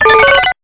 PSION CD 2 home *** CD-ROM | disk | FTP | other *** search / PSION CD 2 / PsionCDVol2.iso / Wavs / TONFOLG ( .mp3 ) < prev next > Psion Voice | 1998-08-27 | 5KB | 1 channel | 8,000 sample rate | 0.08 seconds
TONFOLG.mp3